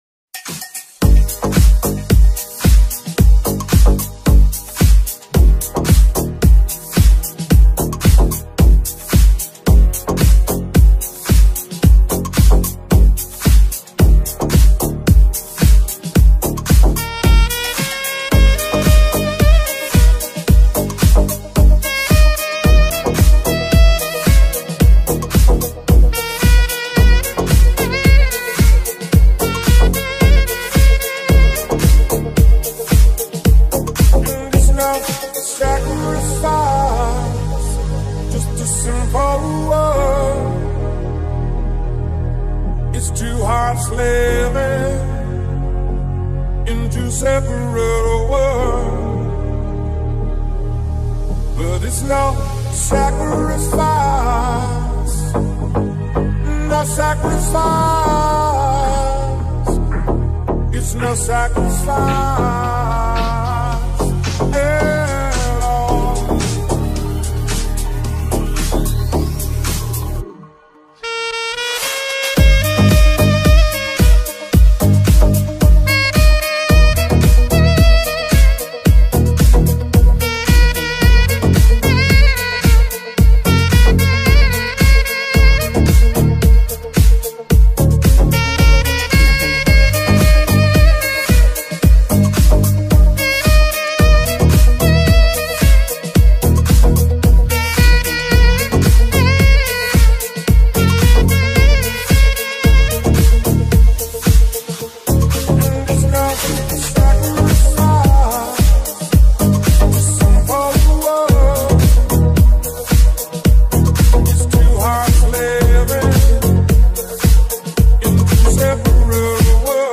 (Sax Deep Cover)